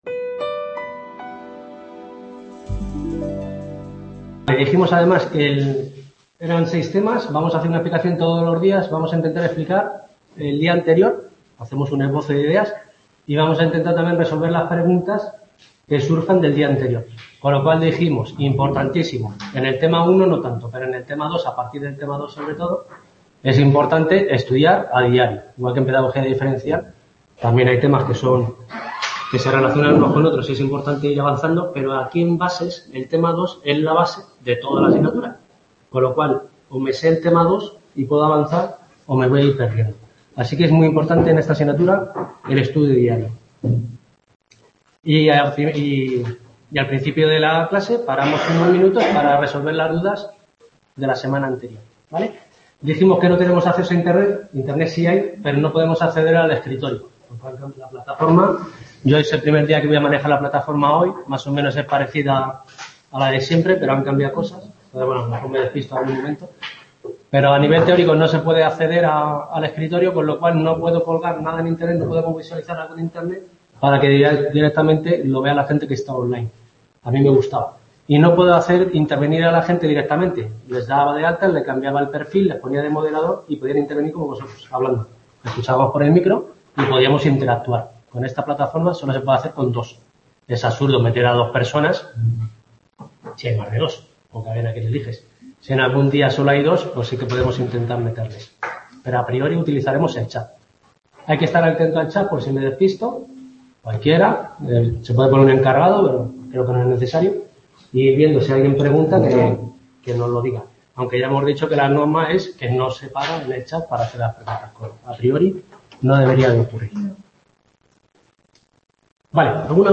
Esta clase la hemos dedicado a explicar los principales conceptos del capítulo 1 y del primer punto del capítulo 2. Capítulo 1: Mente, Cerebro y Educación (MBE) 1.